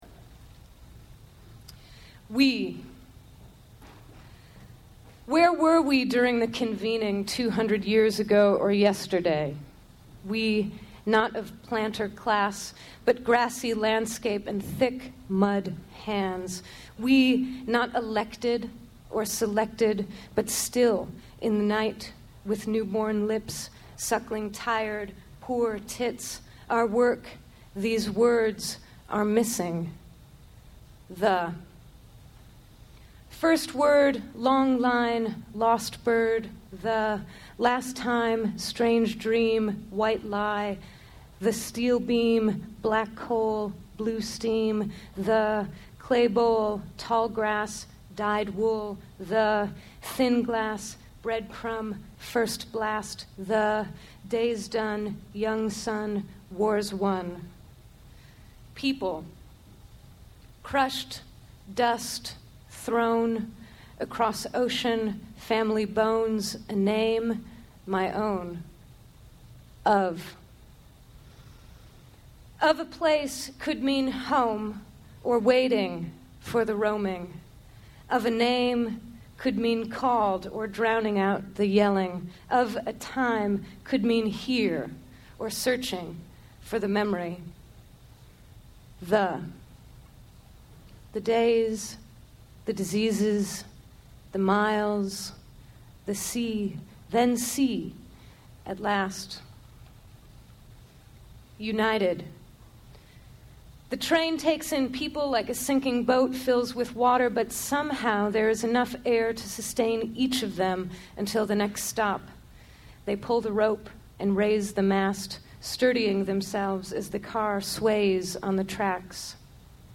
*Recorded live at the Bowery Poetry Club*— Long download, but this is the whole thing.